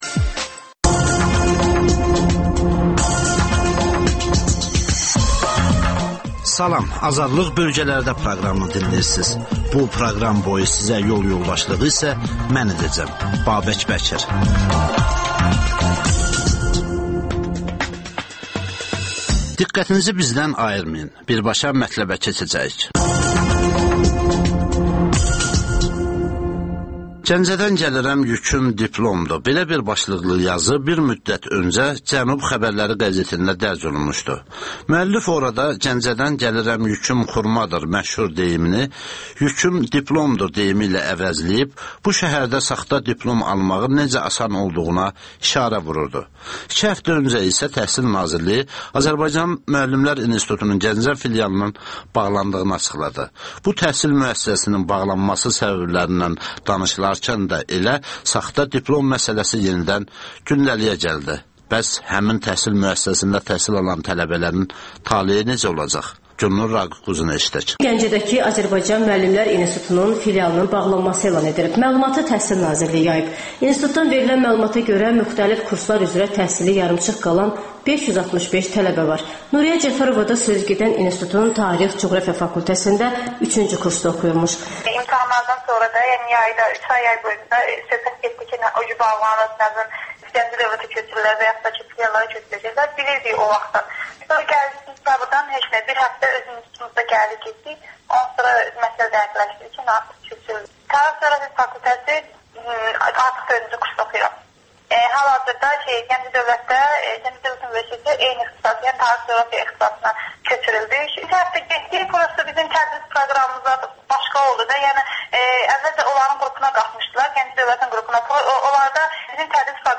Rayonlardan xüsusi reportajlar.